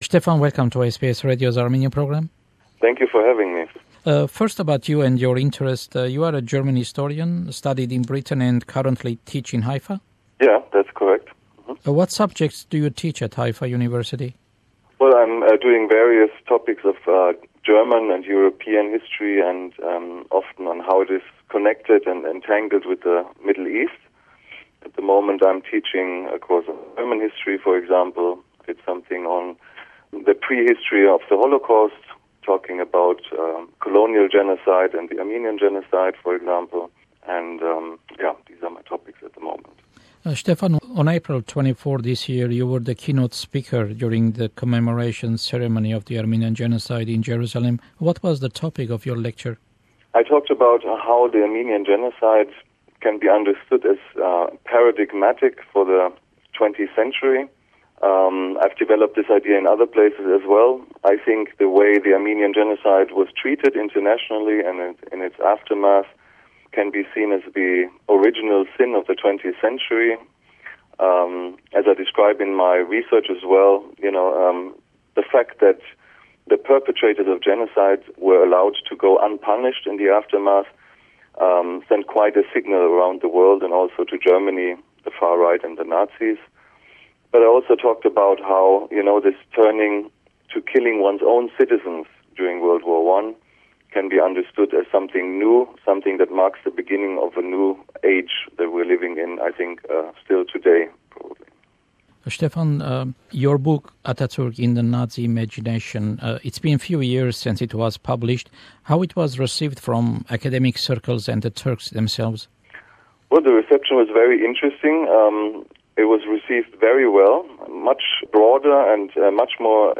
(This is the full length interview).